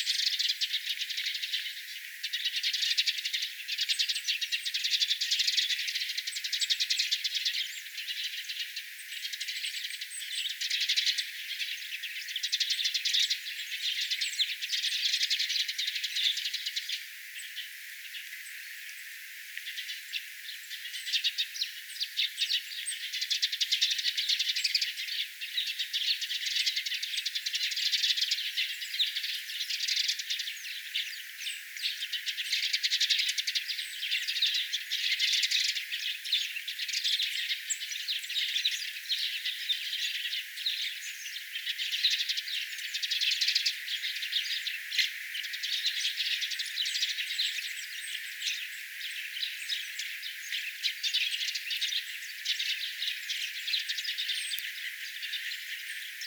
pikkuvarpusten jotain huomioääntelyä,
onko_tuo_pikkuvarpusten_jotain_huomioaantelya_mita_aantelya_se_on.mp3